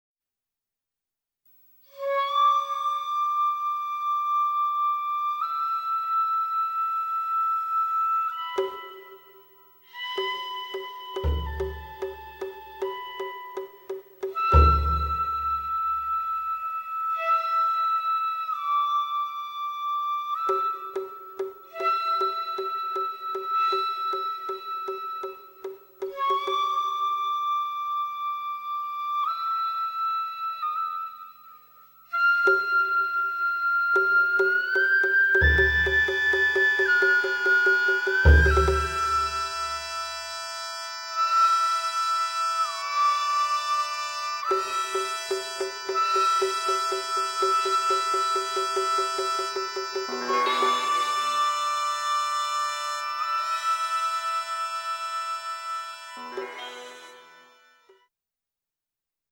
能管や篠笛の元になった雅楽で使われる横笛です。
雅楽の合奏の中にあって、広い音域を生かして低音から高音まで駆け抜ける音色はまさに龍の鳴き声そのものです。
ここで「越天楽」の始めの部分の龍笛を聴いてもらいます。